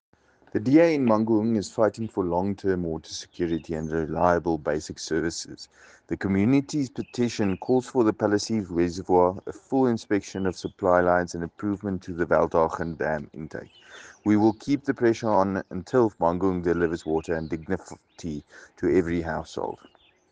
Afrikaans soundbites by Cllr Paul Kotzé and